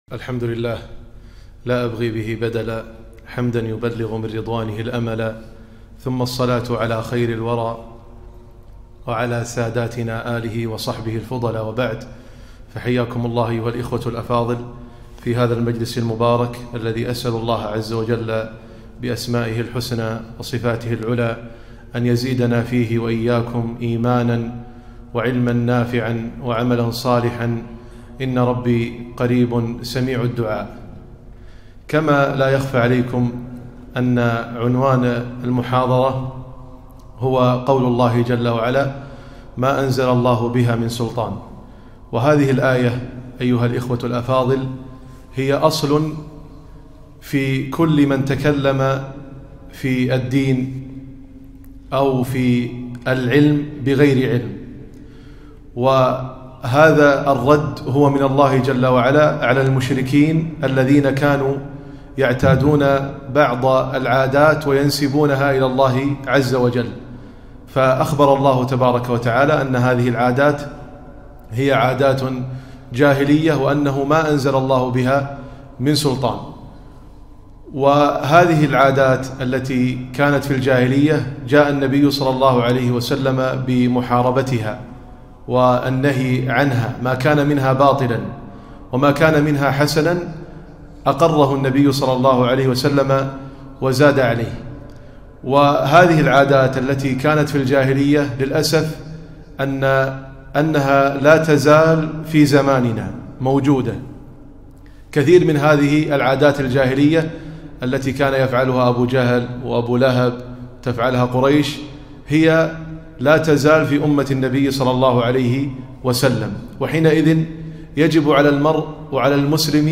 محاضرة - ما أنزل الله بها من سلطان